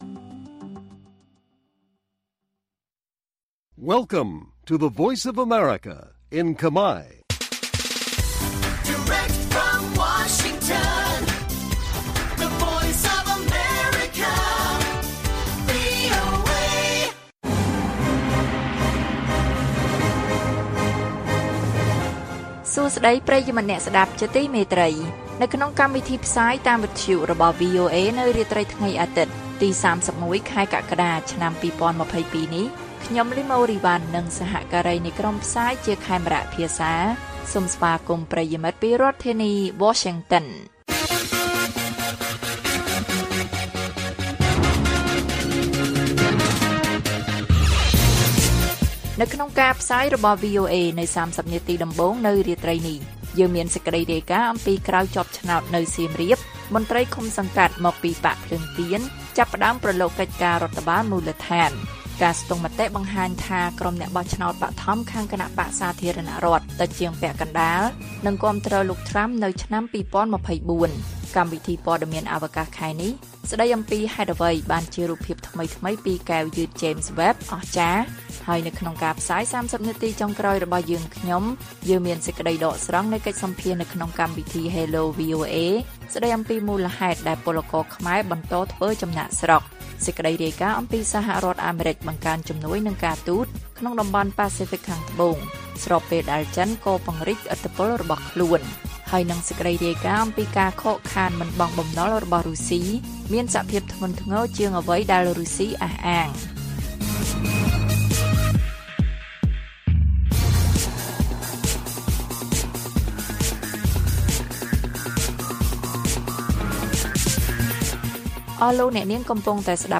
ព័ត៌មាននៅថ្ងៃនេះមានដូចជា ក្រោយជាប់ឆ្នោតនៅសៀមរាប មន្ត្រីឃុំសង្កាត់មកពីបក្សភ្លើងទៀនផ្តើមប្រឡូកកិច្ចការរដ្ឋបាលមូលដ្ឋាន។ សេចក្តីដកស្រង់នៃបទសម្ភាសន៍នៅក្នុងកម្មវិធី Hello VOA ស្តីពី«មូលហេតុដែលពលករខ្មែរបន្តធ្វើចំណាកស្រុក» និងព័ត៌មានផ្សេងទៀត៕